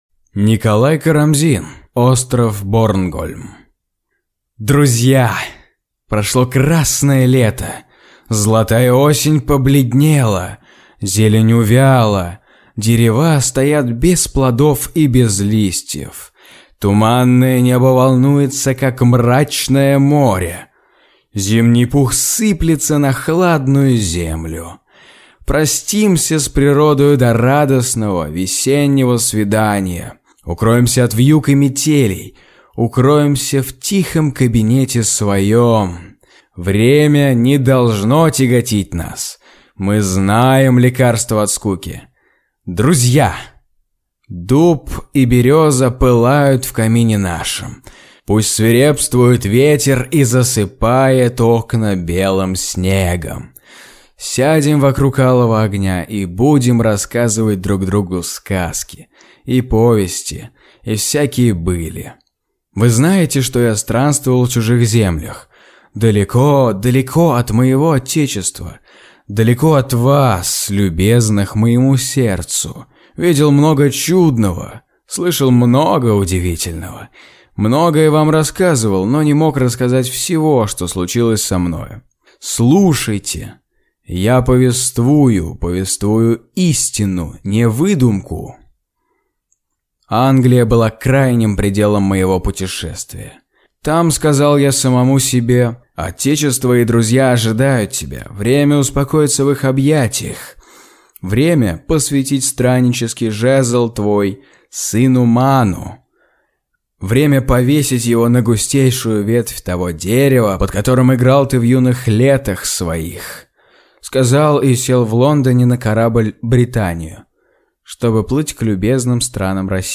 Аудиокнига Остров Борнгольм | Библиотека аудиокниг